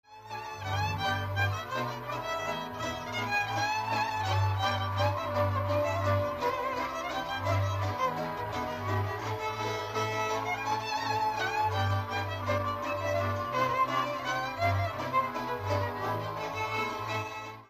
Dallampélda: Hangszeres felvétel
Erdély - Maros-Torda vm. - Mezőbánd
hegedű
brácsa, bőgő
cimbalom
Műfaj: Jártatós
Stílus: 1.1. Ereszkedő kvintváltó pentaton dallamok
Kadencia: 7 (5) b3 1